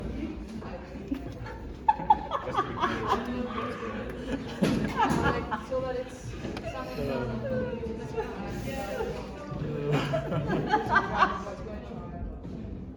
File:Laughter in distance.mp3 - XPUB & Lens-Based wiki
Laughter_in_distance.mp3